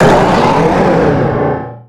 Cri de Méga-Drattak dans Pokémon Rubis Oméga et Saphir Alpha.
Cri_0373_Méga_ROSA.ogg